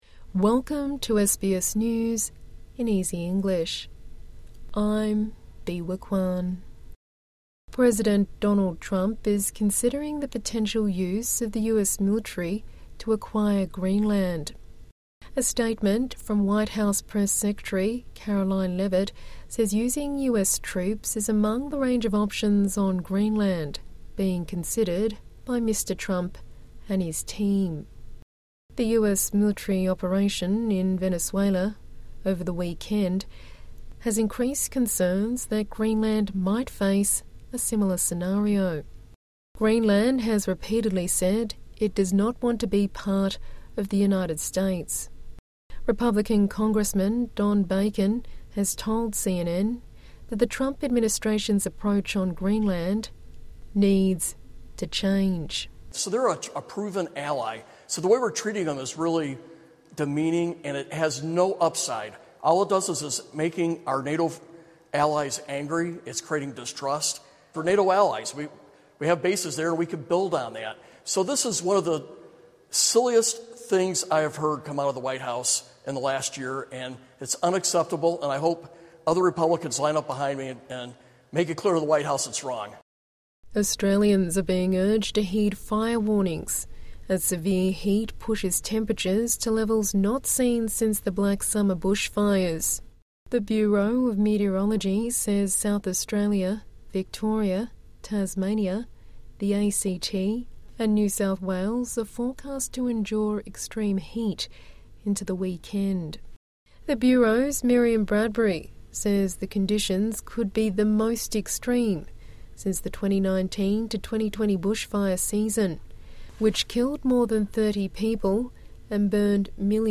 A daily 5 minute news bulletin for English learners, and people with a disability.